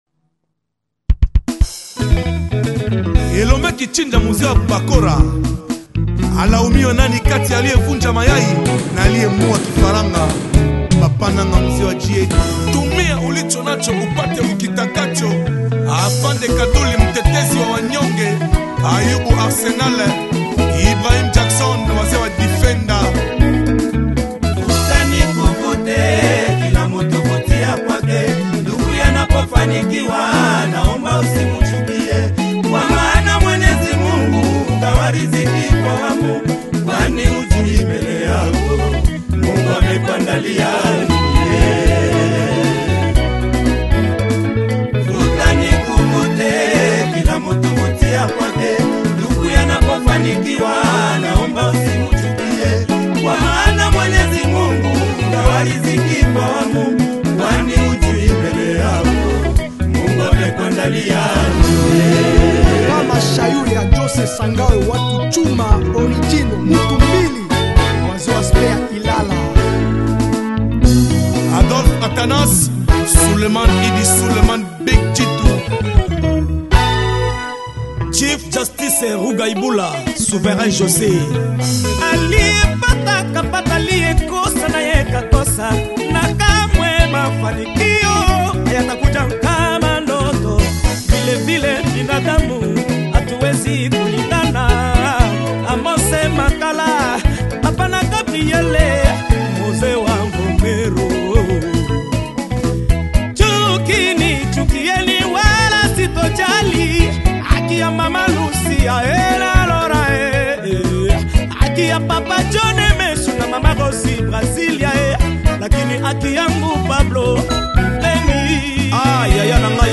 Enjoy the legendary Tanzanian dance sound